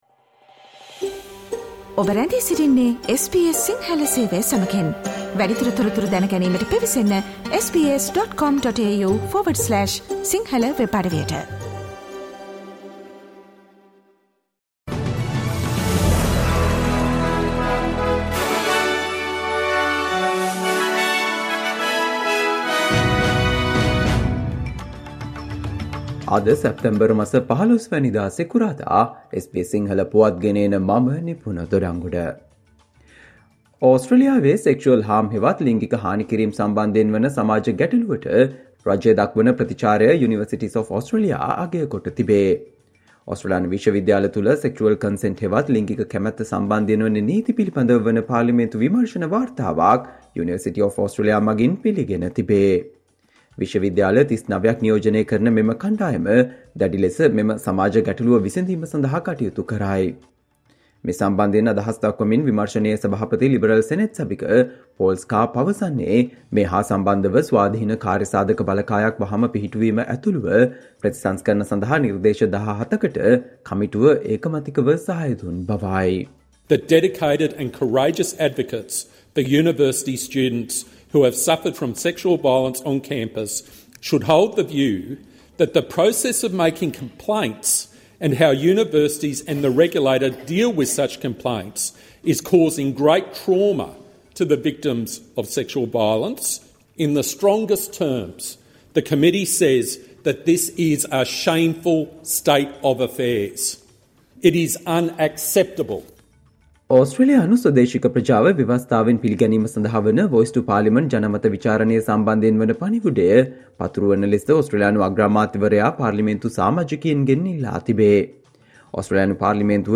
Australia news in Sinhala, foreign and sports news in brief - listen, today - Friday 09 September 2023 SBS Radio News